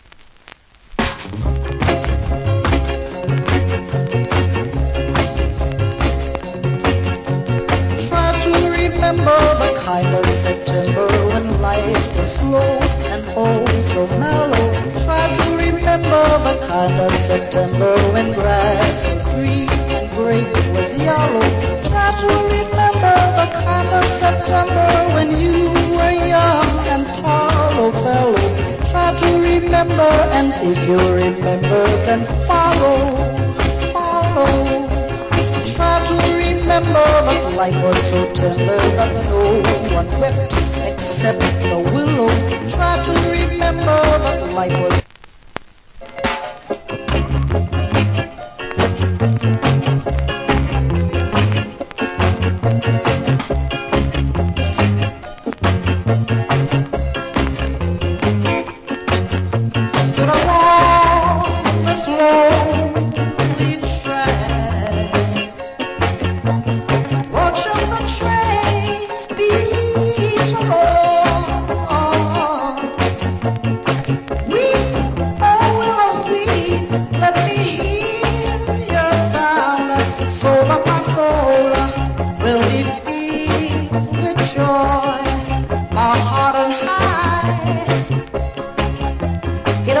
Old Hits Early Reggae.